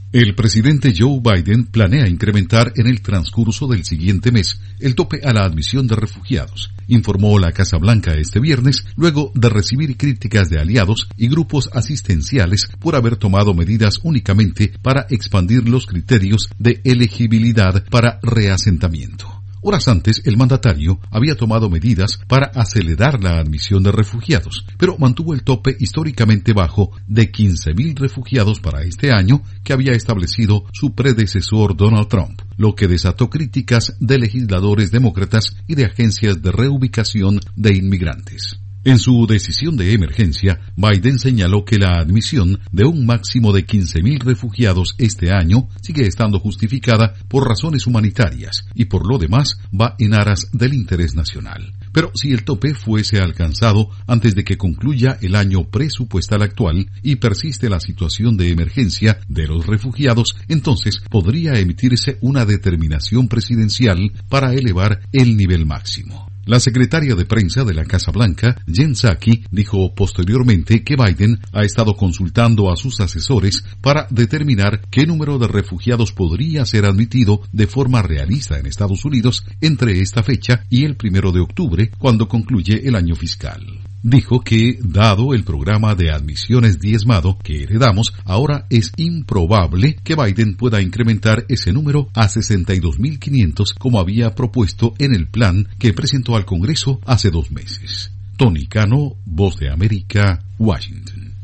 La Casa Blanca asegura que Biden elevará el tope de refugiados en mayo. Informa desde la Voz de América en Washington